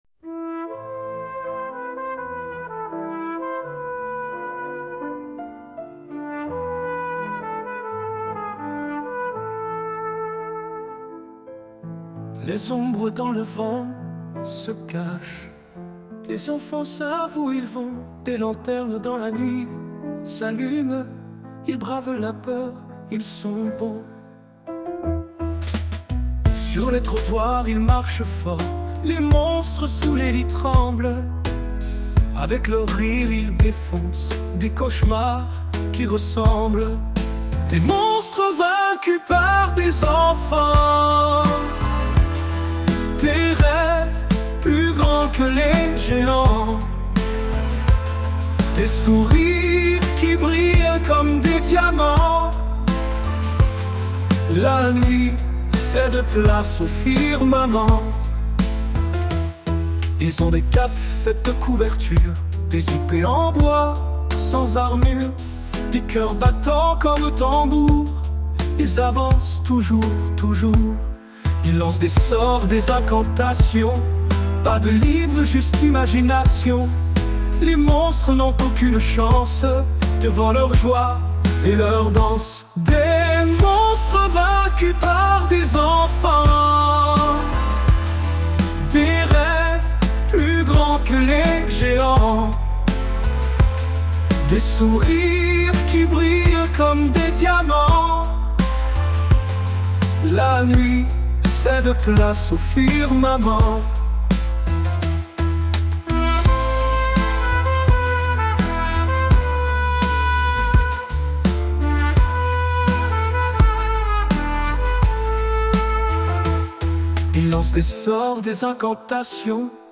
Une chanson de 2 minutes 43 ;